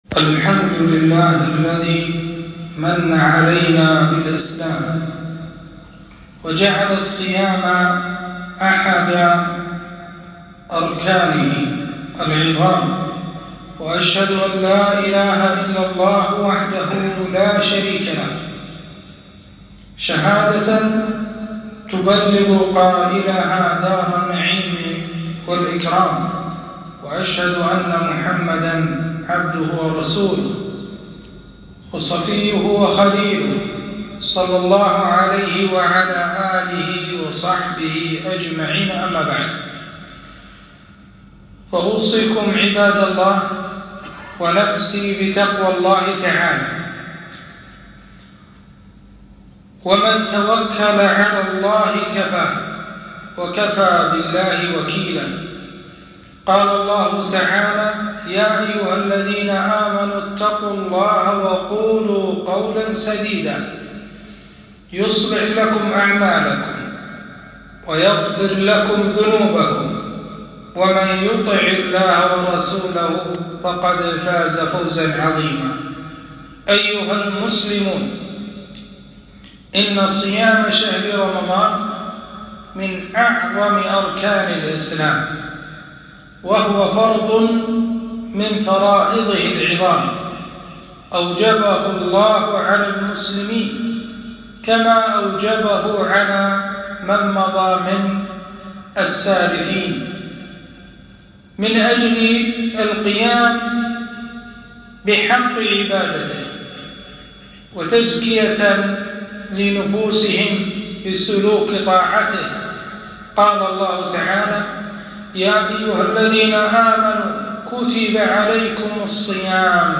خطبة - أحكام وآداب الصيام